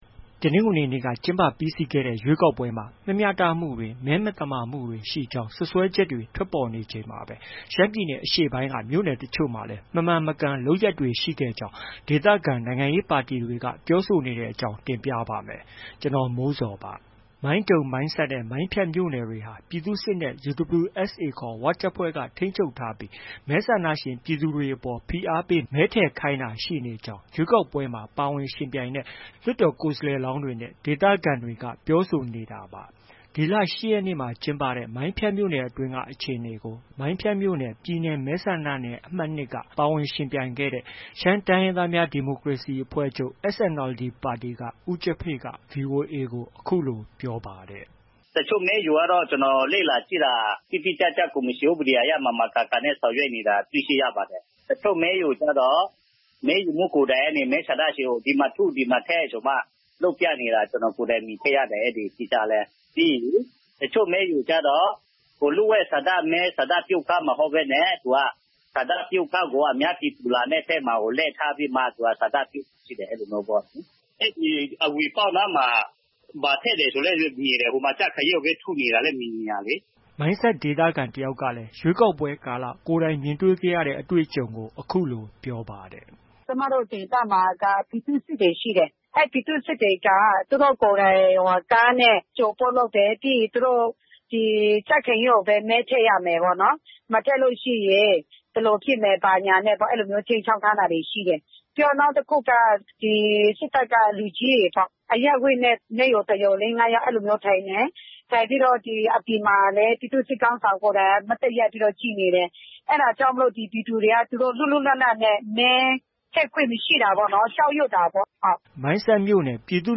မိုင်းဆတ်ဒေသခံ တစ်ယောက်ကလည်း ရွေးကောက်ပွဲကာလ ကိုယ်တိုင်မြင်ခဲ့ရတဲ့ အတွေ့အကြုံကို အခုလို ပြောပါတယ်။